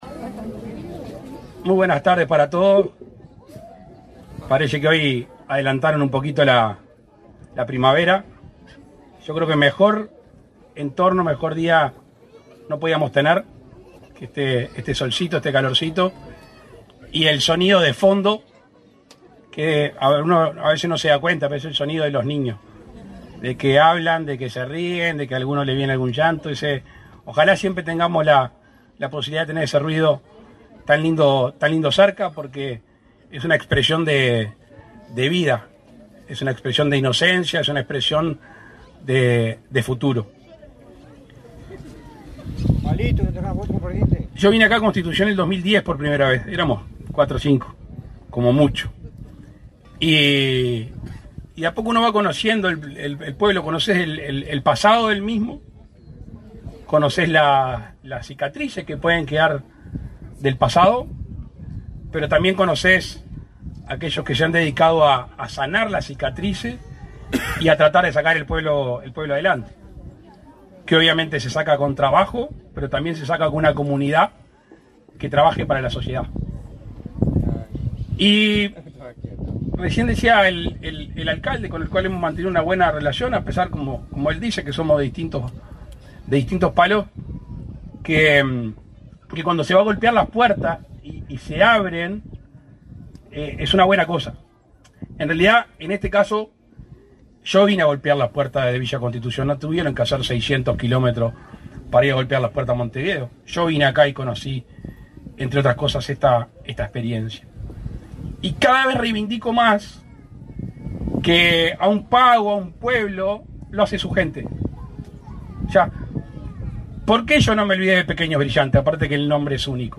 Palabras del presidente de la República, Luis Lacalle Pou
El presidente de la República, Luis Lacalle Pou, participó, este 5 de setiembre, en la inauguración de un centro de atención a la infancia y la